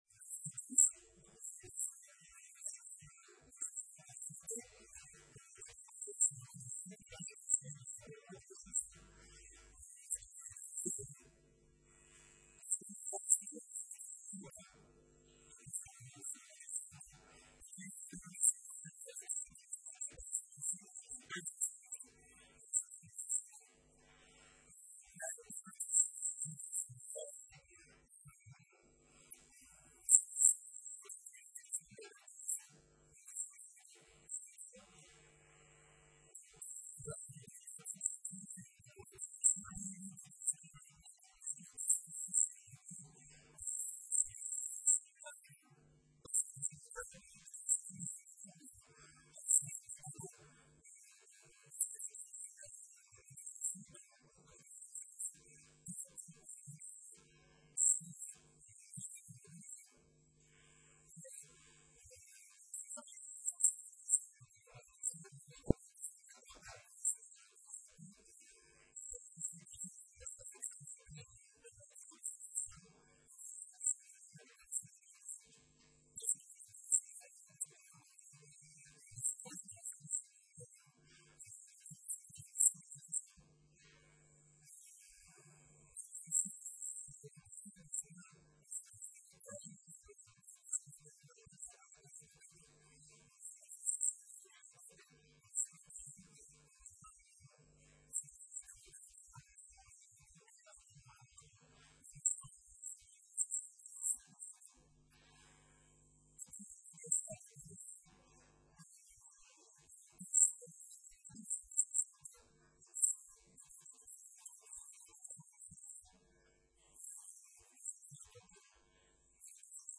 9e matinée des Œnologues - Bordeaux